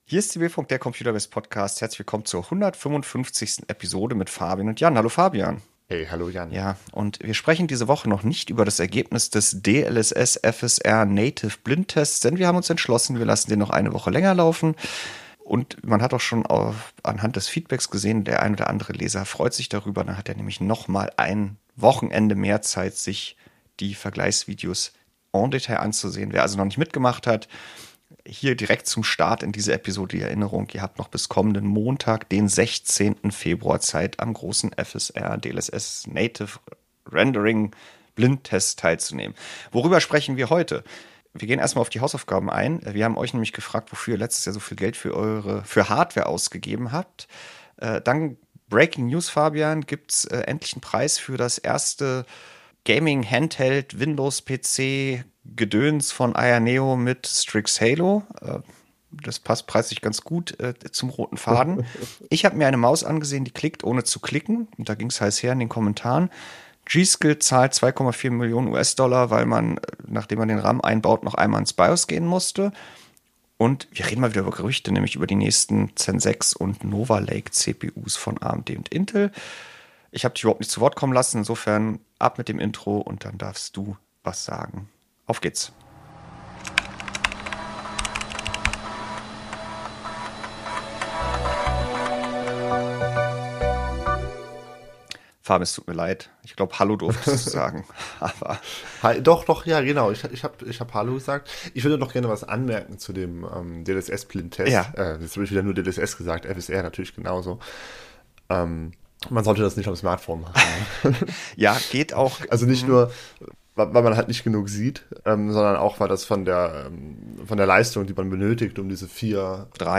Zum Glück war die Leitung (ohne Powerline!) schnell genug, so dass die Daten bis dahin schon "on the fly" auf den Servern gelandet waren.